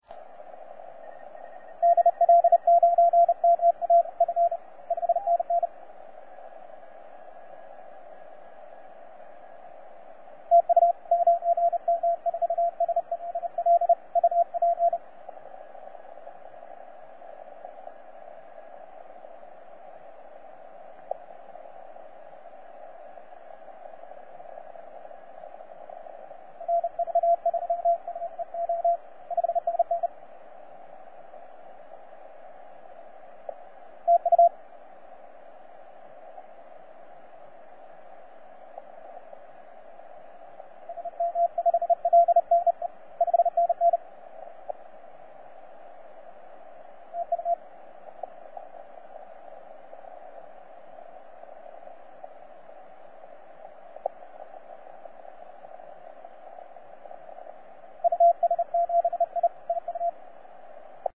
9M4SLL 15CW